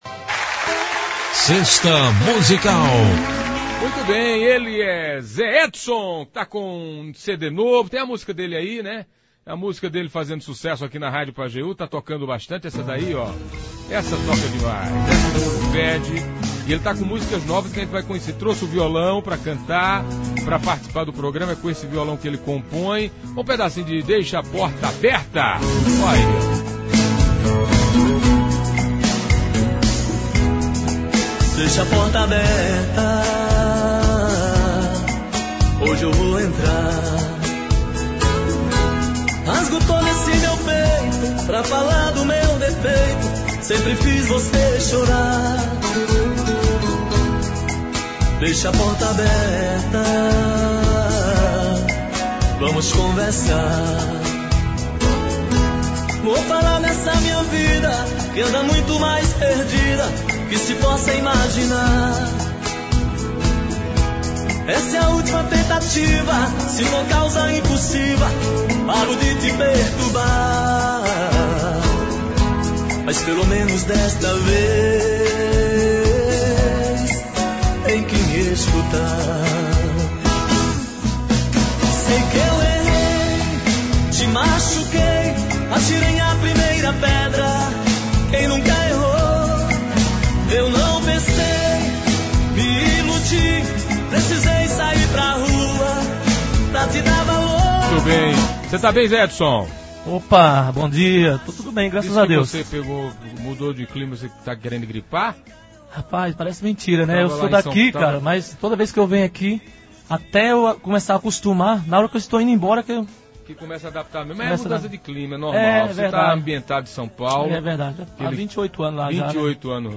cantar ao vivo